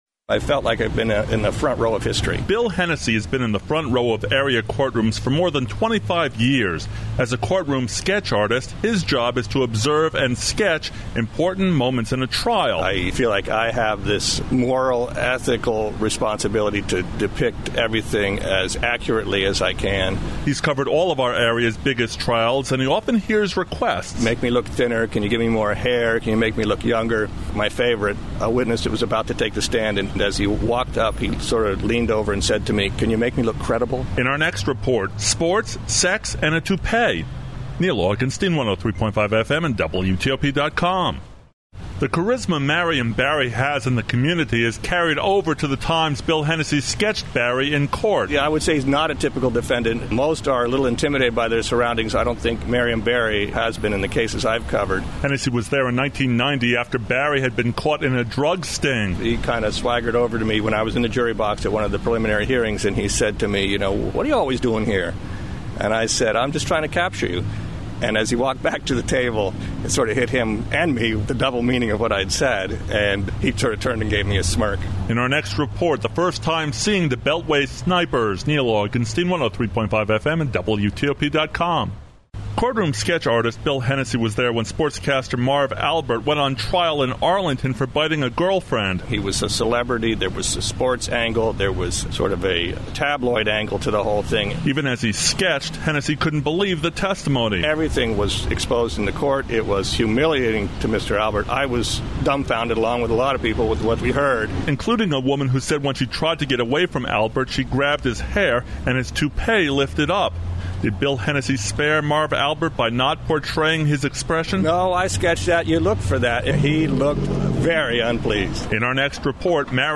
WTOP News Radio Segment